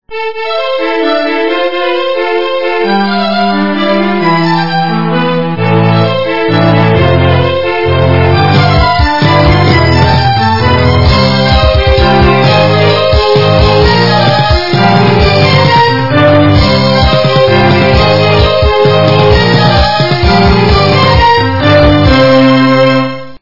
- западная эстрада